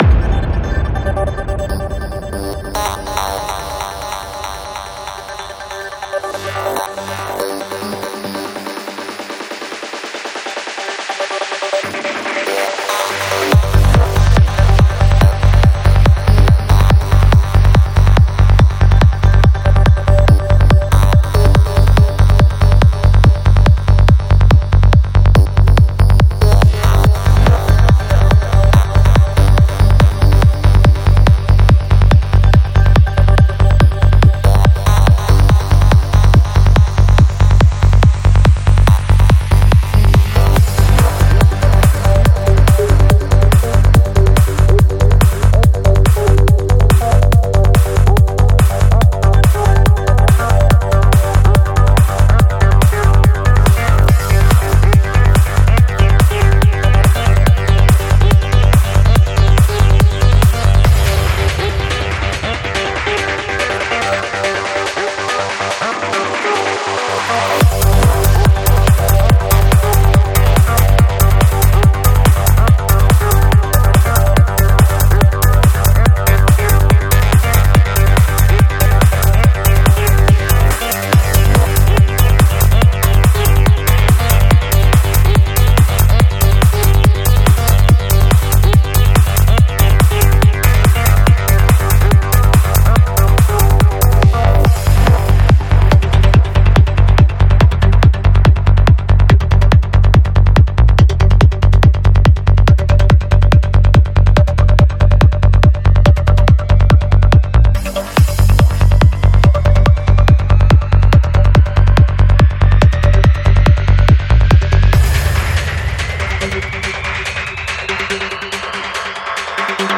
Жанр: Psychedelic